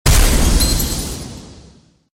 stagger limbus company Meme Sound Effect